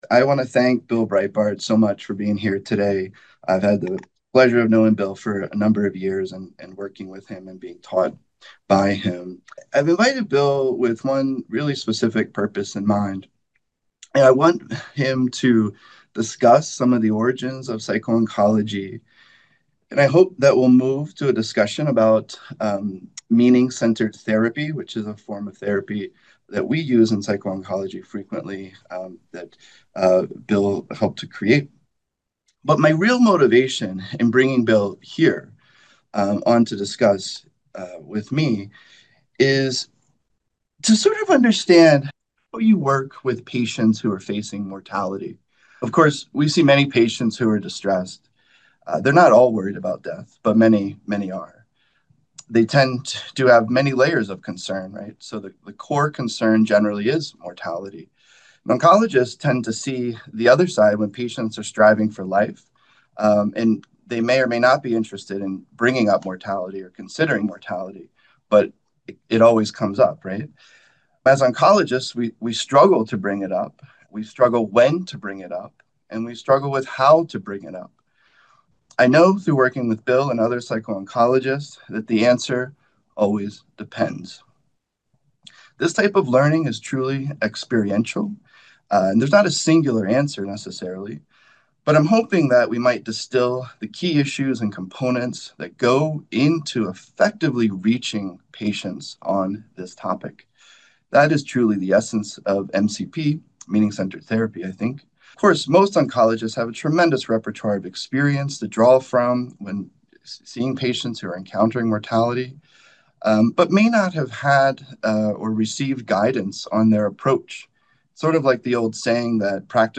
How To Discuss Death? A Conversation of Mortality in Cancer Care